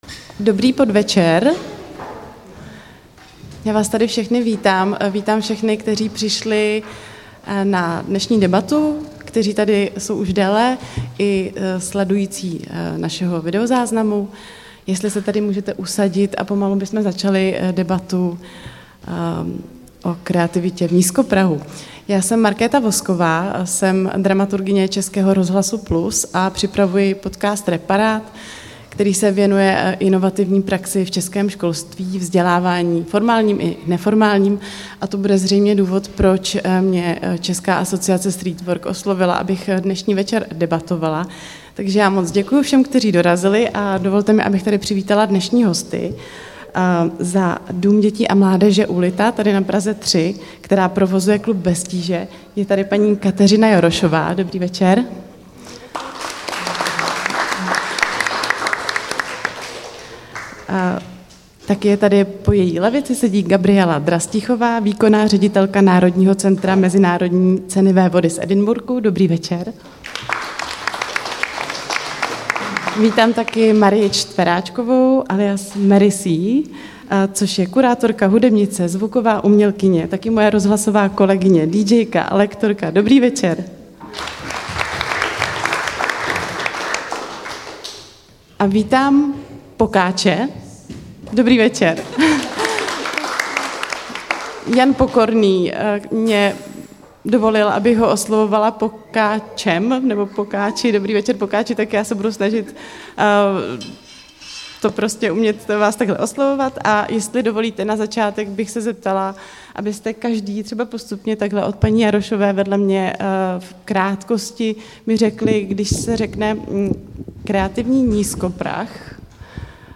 Ve speciální epizodě podcastové série přinášíme záznam z veřejné diskuze v rámci letošního Týdne nízkoprahových klubů.